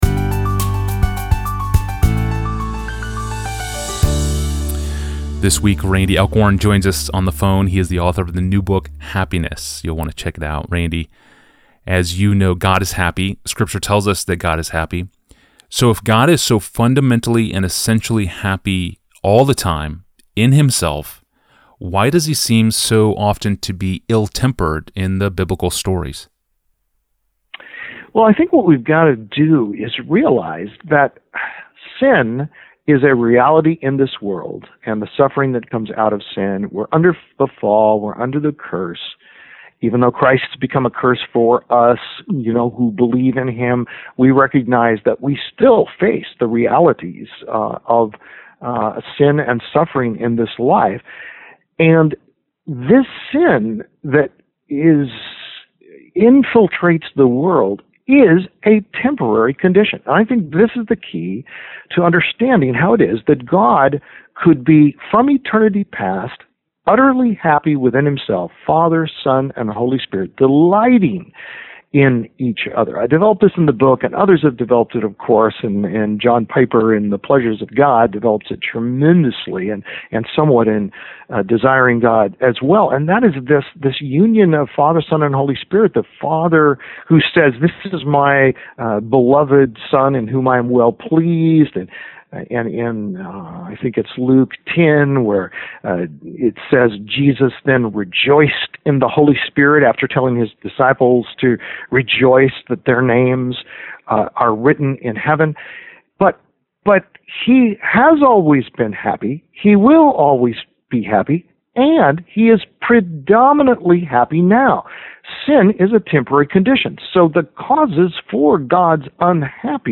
the audio of this interview.